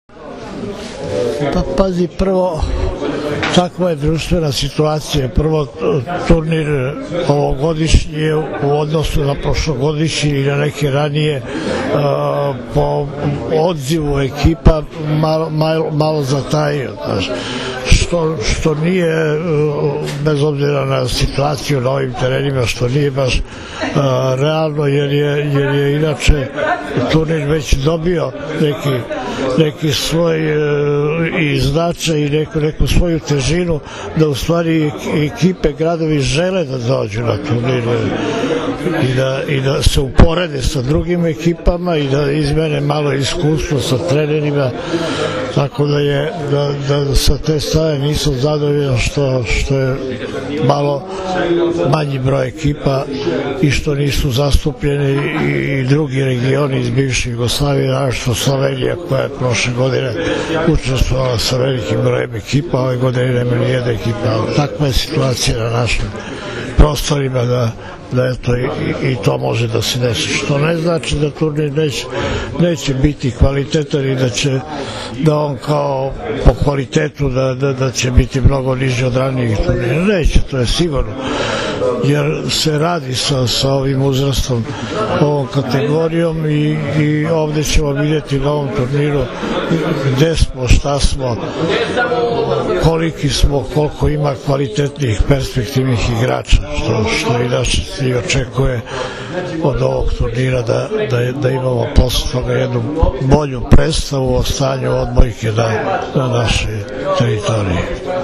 Povodom „Trofeja Beograd 2014.“ – 49. Međunarodnog turnira Gradskih omladinskih reprezentacija, koji će se odigrati od 1. – 3. maja, danas je u sali Gradske uprave Grada Beograda održana konferencija za novinare.